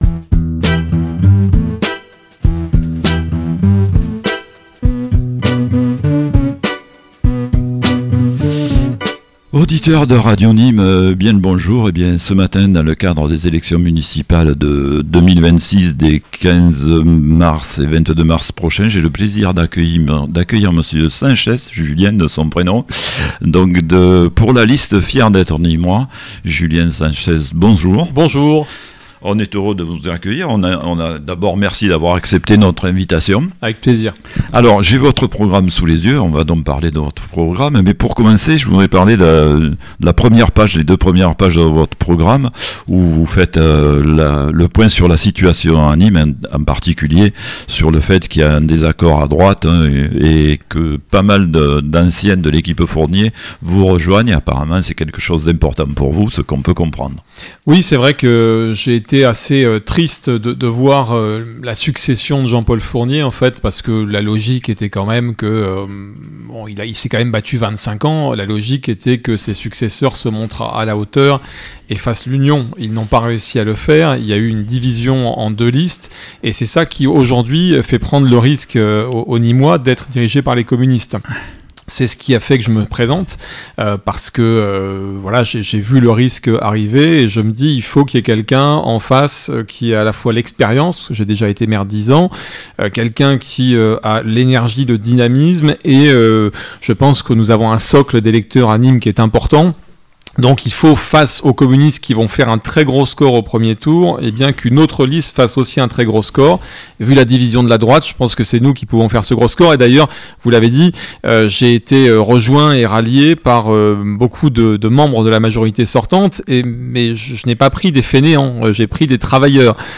Municipales 2026 - Entretien avec Julien SANCHEZ (Fier d'être nîmois) - EMISSION DU 11 MARS
Émission spéciale élections municipales 2026